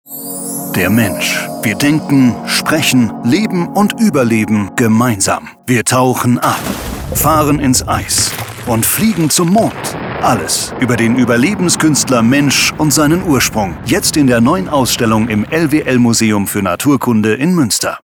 rz_radiospot_lwl_ausstellung_mensch_v1_01.mp3